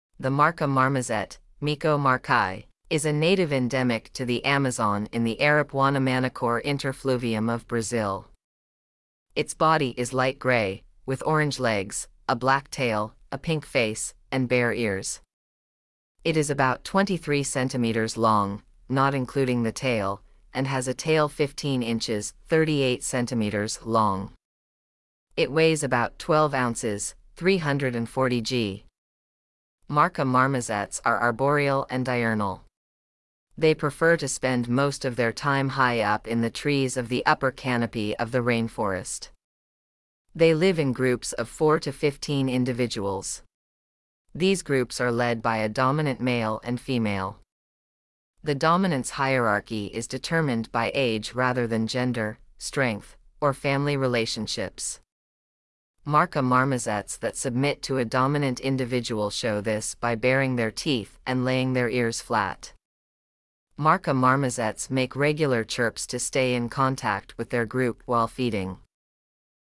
• Marca marmosets make regular chirps to stay in contact with their group while feeding.
Marcas-Marmoset.mp3